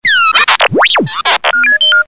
Ideal para tonos de mensaje.